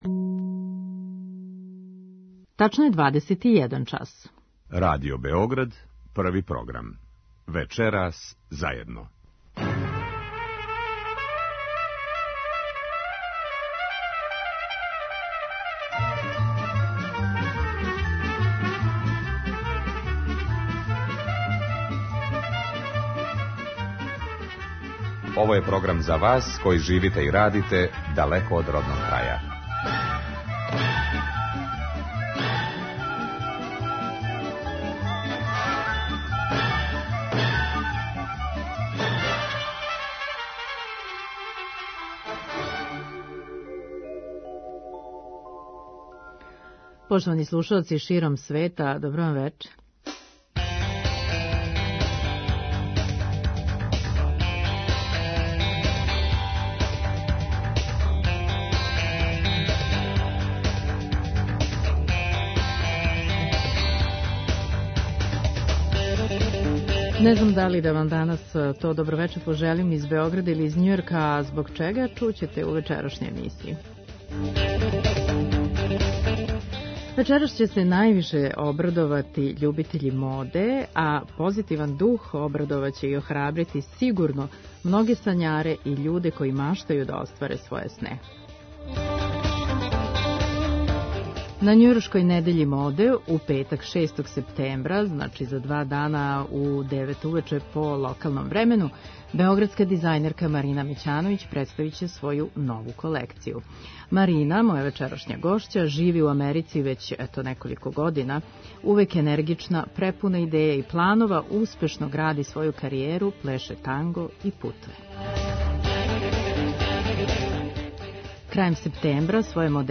Емисија магазинског типа која се емитује сваког петка од 21 час.